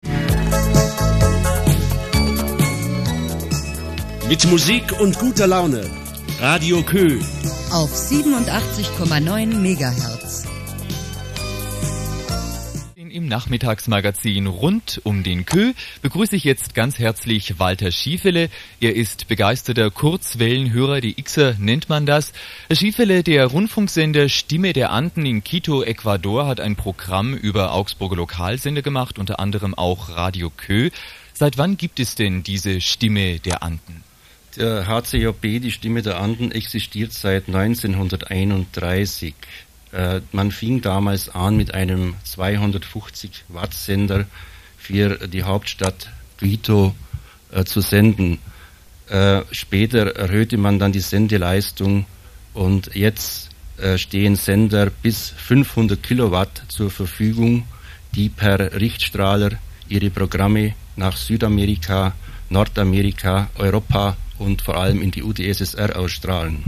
Dieses Interview wurde übrigens kurz darauf in der DX-Sendung von HCJB wiederholt.